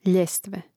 ljȅstve ljestve